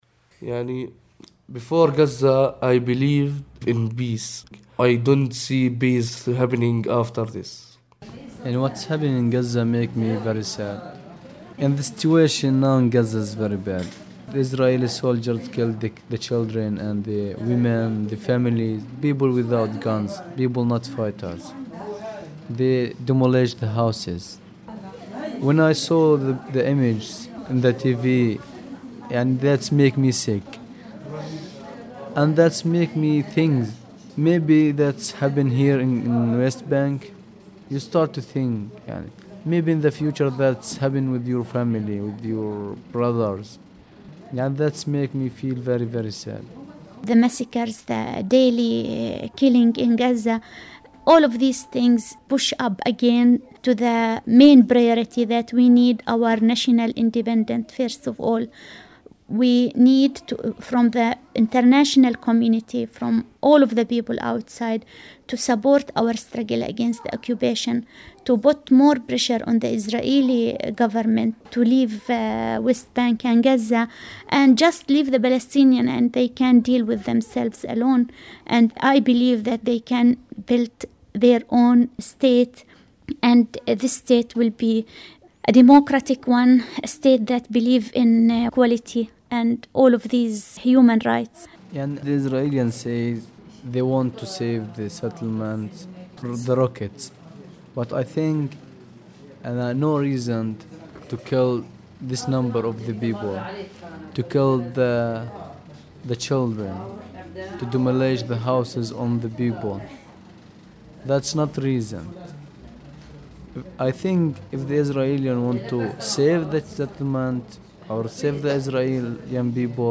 The youth of Aida camp are no exception. Here’s what some of them had to say.